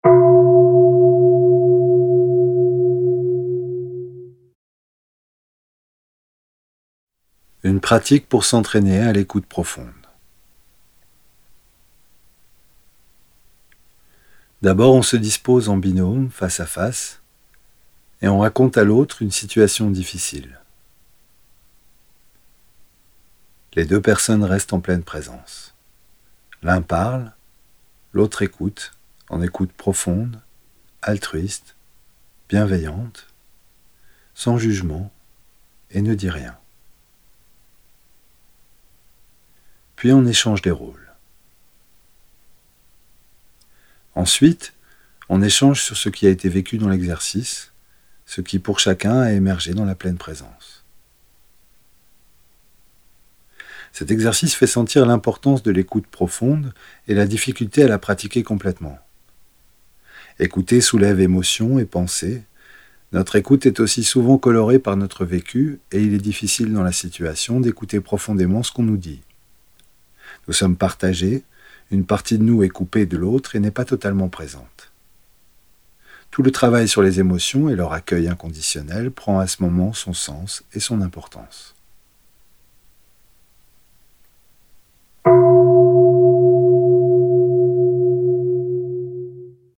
Audio homme
23.ETAPE-6-AUDIO-P40-HOMME.mp3